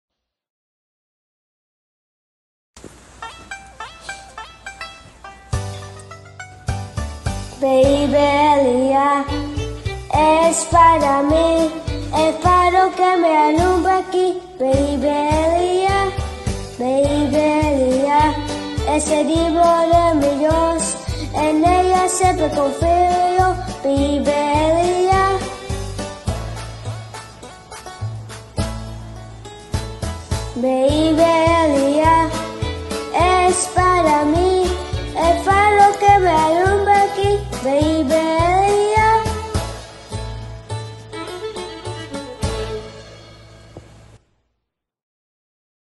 corito